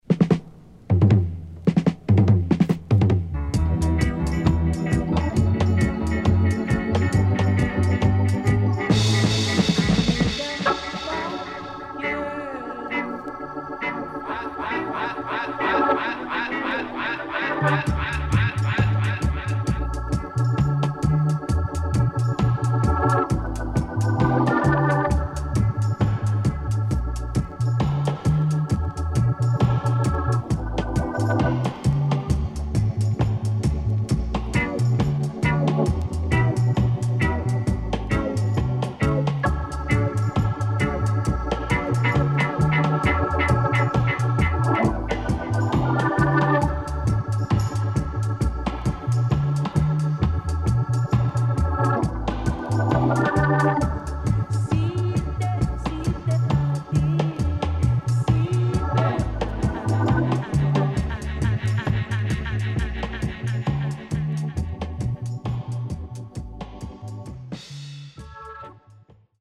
HOME > Back Order [VINTAGE 7inch]  >  STEPPER  >  INST 70's
CONDITION SIDE A:VG+
SIDE A:少しチリノイズ入ります。